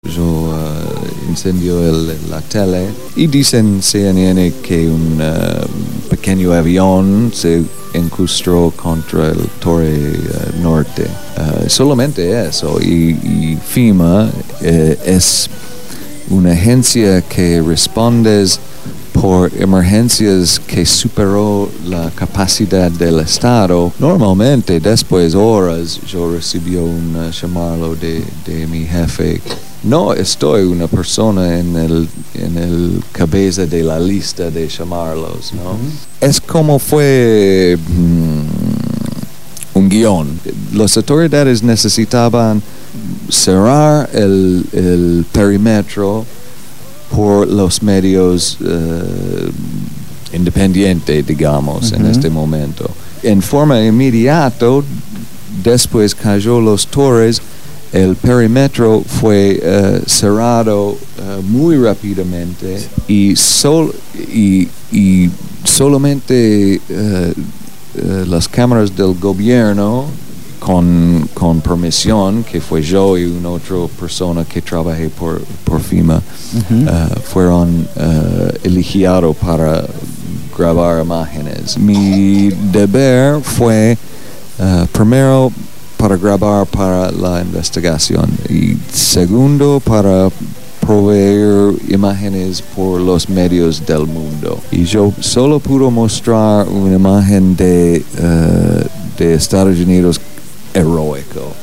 en los estudios de Radio Gráfica FM 89.3